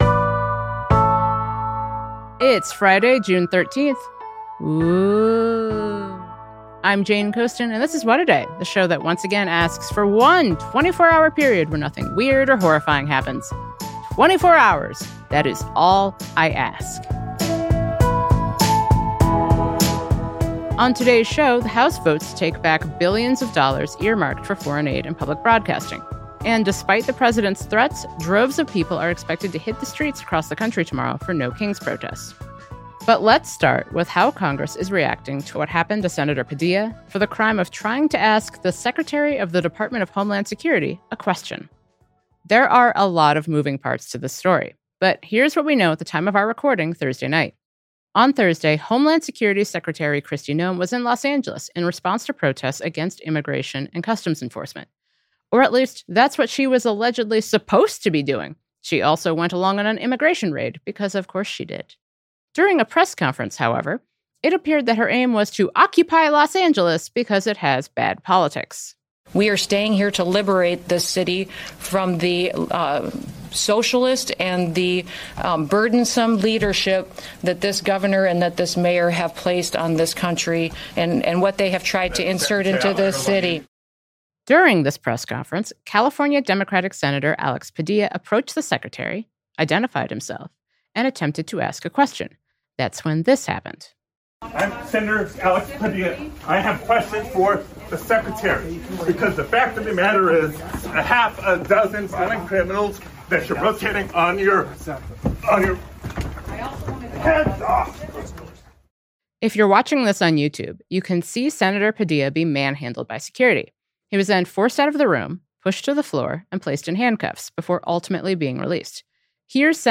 Congress is reeling after Democratic US Senator Alex Padilla was forcibly removed and handcuffed – all for the crime of trying to ask Homeland Security Secretary Kristi Noem a question. There are lots of moving parts to this story, so to help us understand what happened to the California Senator, we spoke with his counterpart, US Democratic Senator Adam Schiff of California.